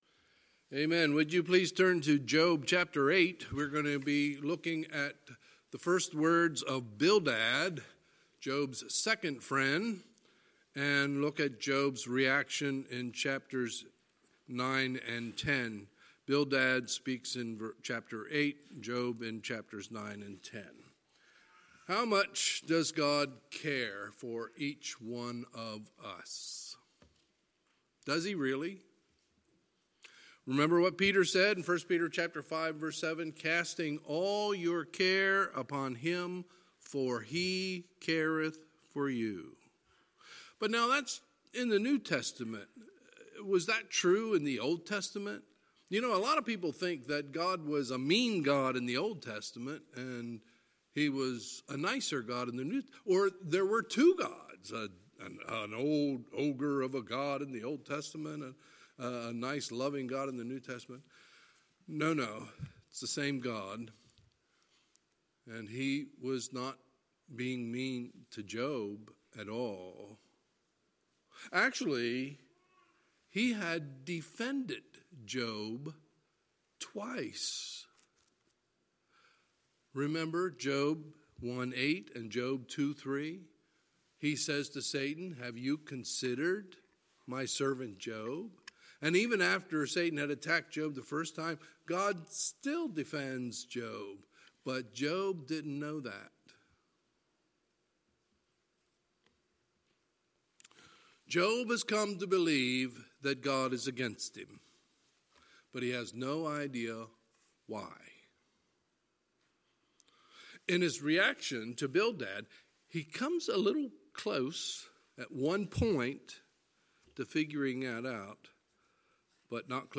Sunday, February 2, 2020 – Sunday Evening Service